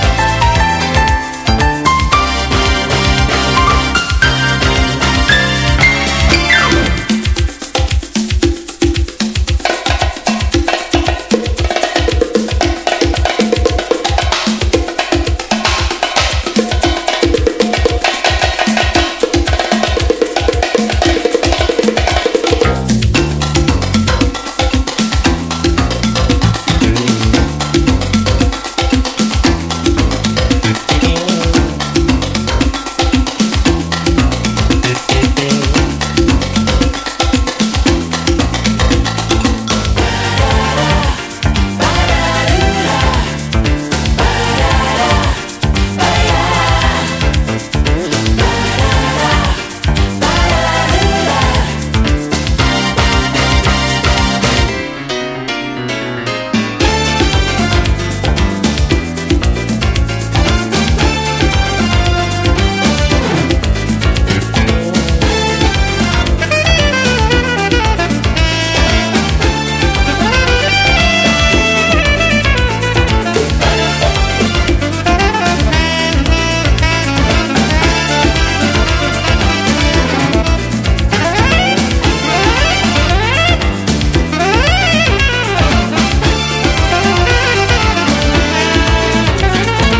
DRUM'N'BASS / BREAKBEATS
硬質ダウンビートなリミックス！